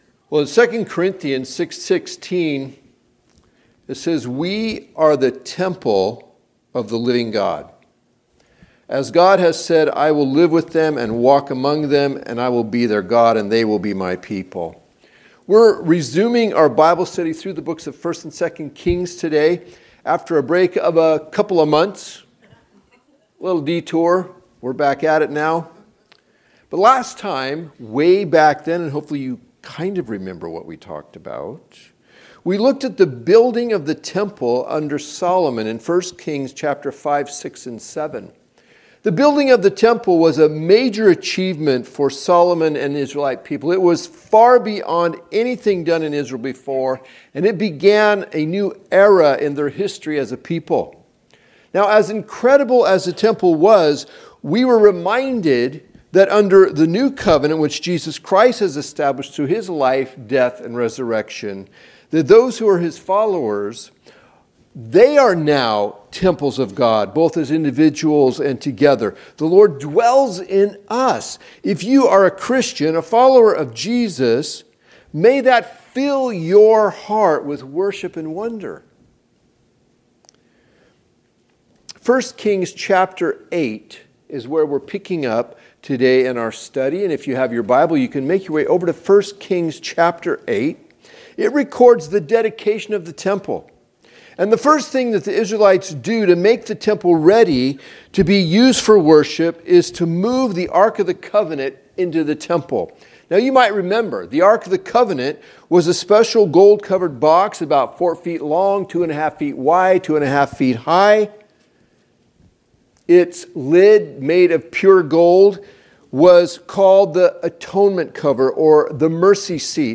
Recordings of the teaching from the Sunday morning worship service at Touchstone Christian Fellowship. Tune in each week as we move through the Bible in a way that is both relevant and challenging.